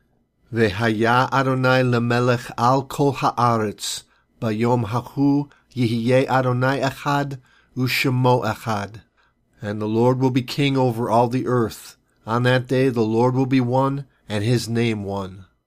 Zechariah 14:9 Hebrew Lesson